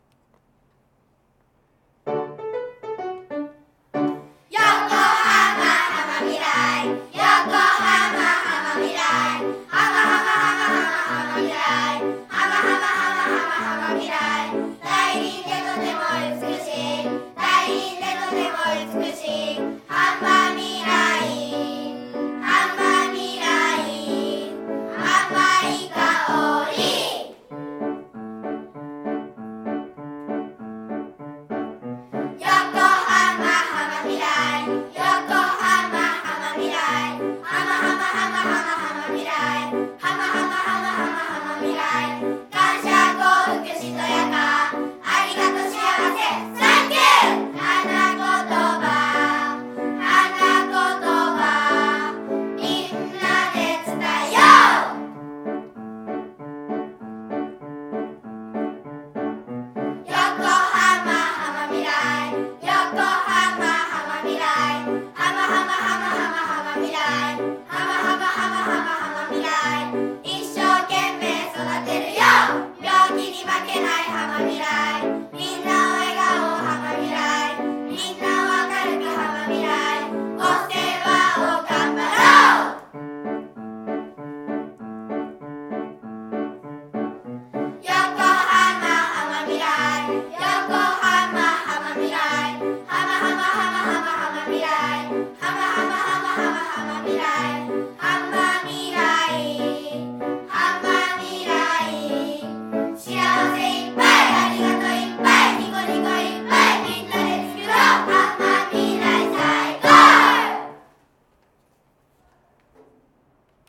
音楽の時間に収録したとのことで、今日は、ホームページの☆今日の台小☆でお披露目したいと思います。子どもたちの歌声をぜひ聞いてください！！